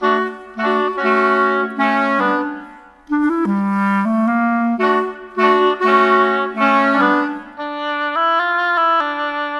Rock-Pop 10 Winds 01.wav